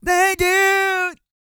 E-GOSPEL 239.wav